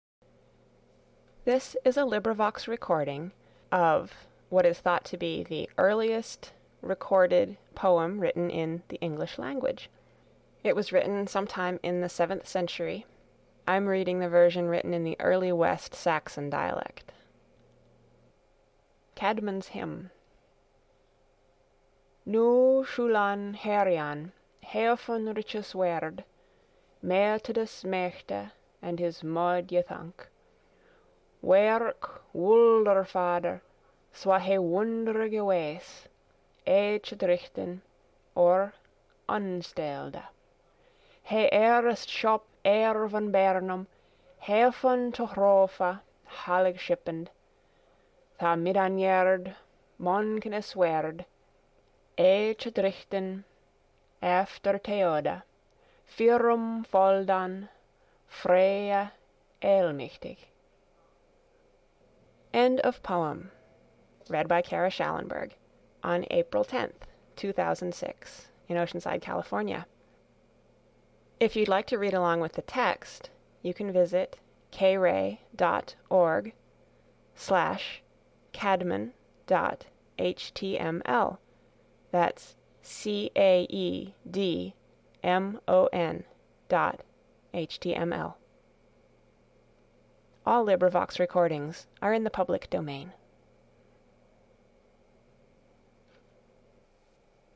"Caedmon's Hymn"read.